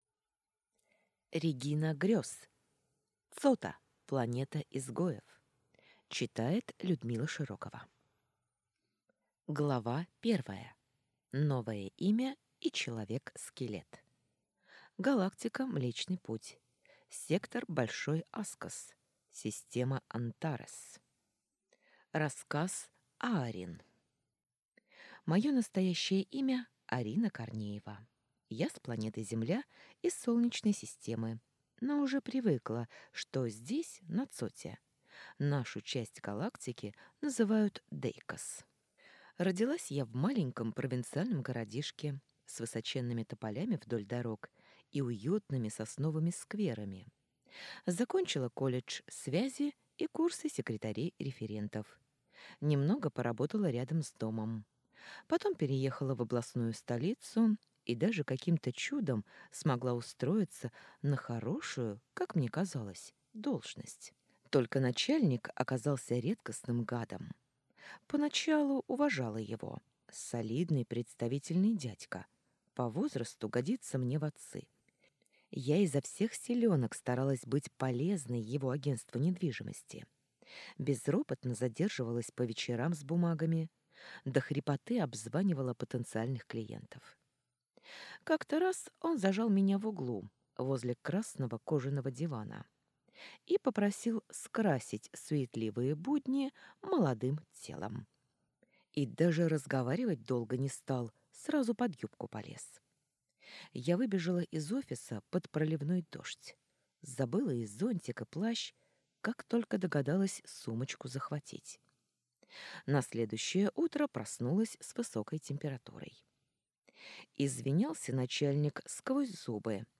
Аудиокнига Цота. Планета изгоев | Библиотека аудиокниг
Прослушать и бесплатно скачать фрагмент аудиокниги